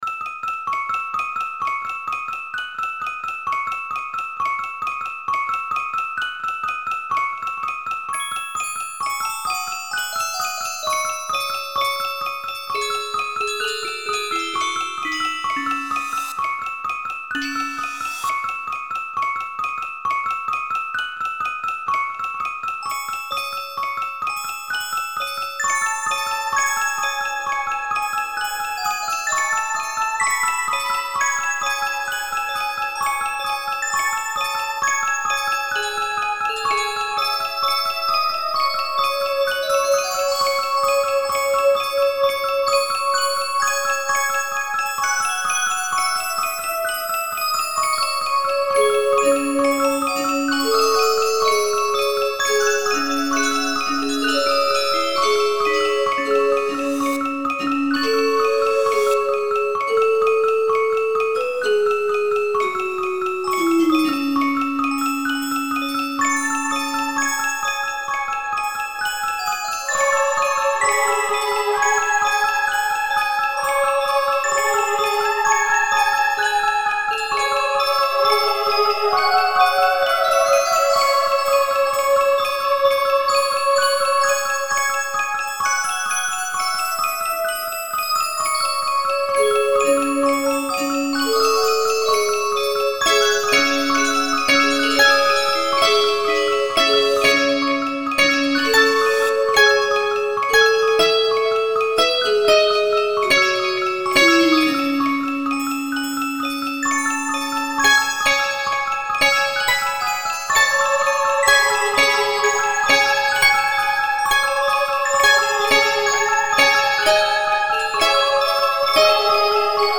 Posted in Dubstep, Other Comments Off on